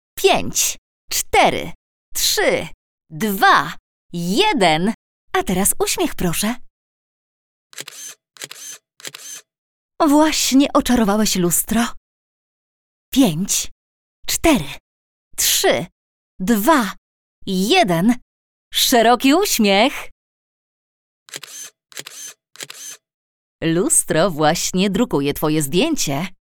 Female 30-50 lat
One of the most experienced female voice artists in the industry, with a clear, soft and pleasant voice.